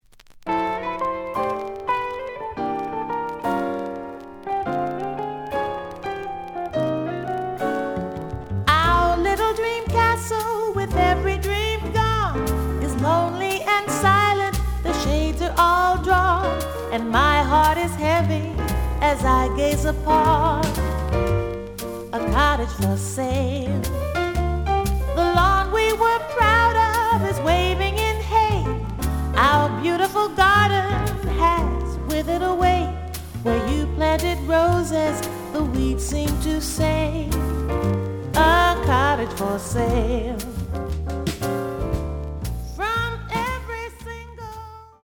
The audio sample is recorded from the actual item.
●Genre: Vocal Jazz
Slight edge warp. But doesn't affect playing. Plays good.)